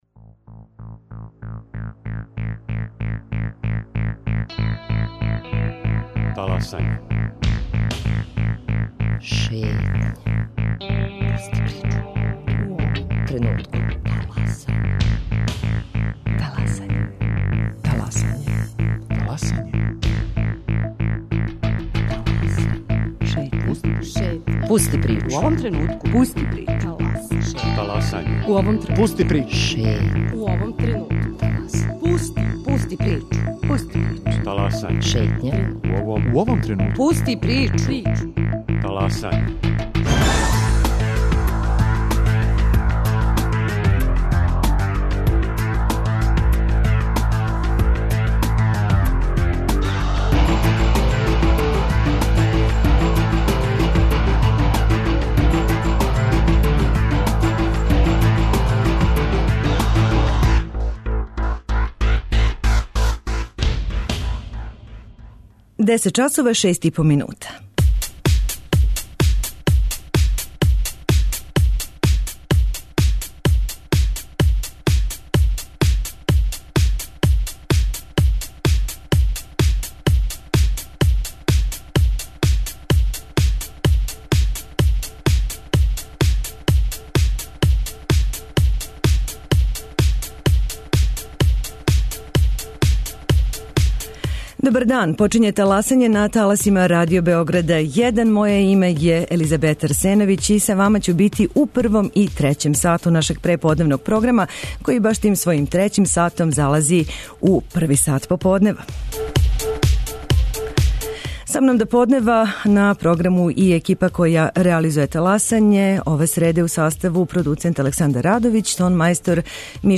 чућете најинтересантније делове са представљања књиге